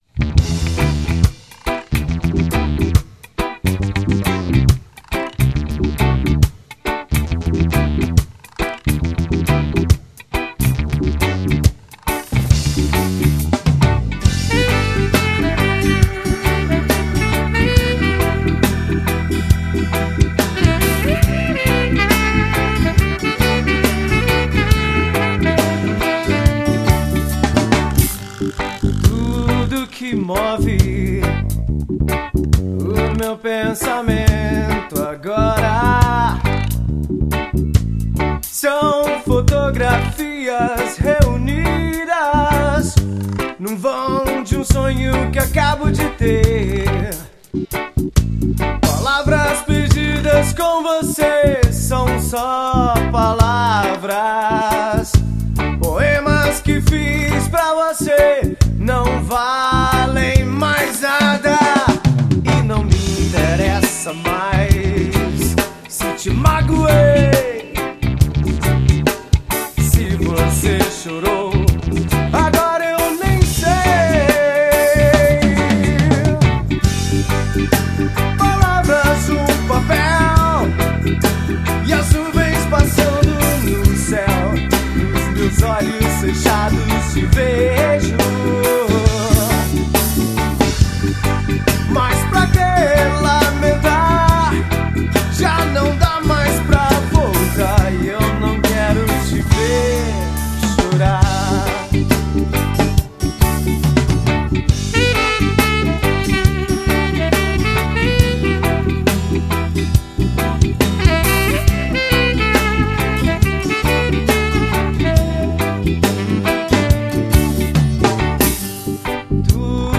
1976   03:58:00   Faixa:     Reggae